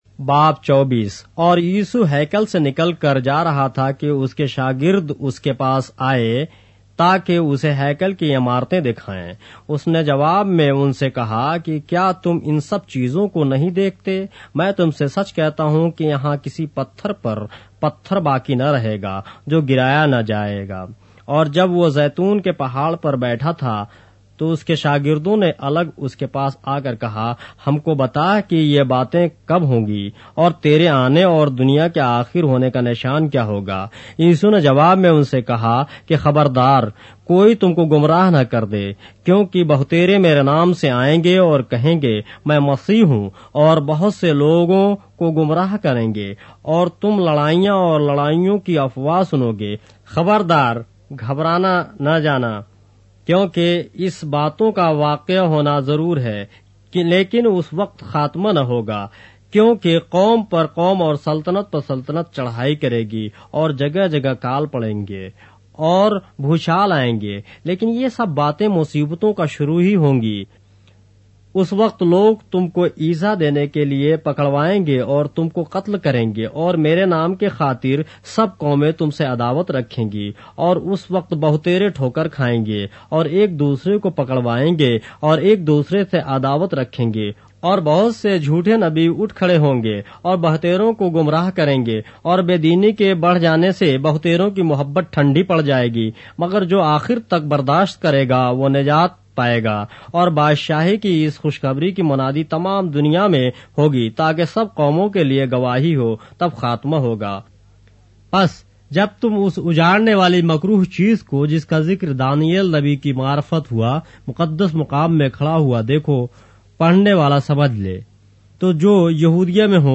اردو بائبل کے باب - آڈیو روایت کے ساتھ - Matthew, chapter 24 of the Holy Bible in Urdu